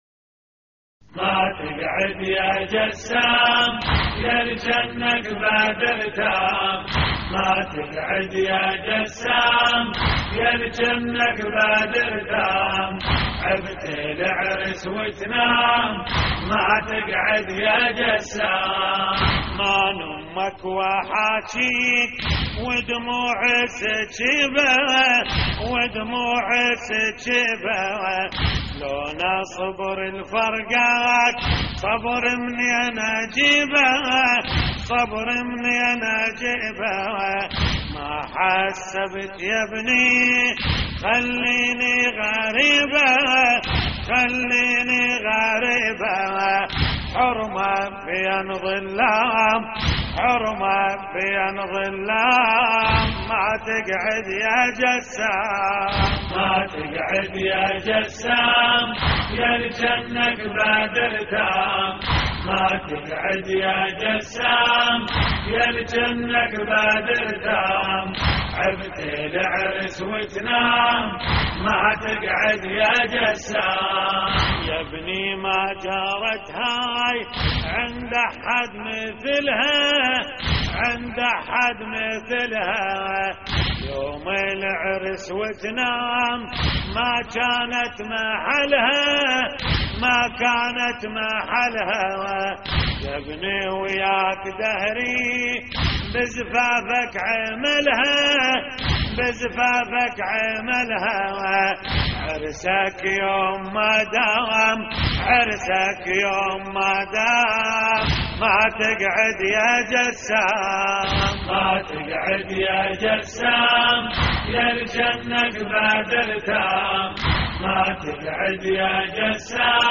اللطميات الحسينية ما تقعد يا جسام يالكنك بدر تام - استديو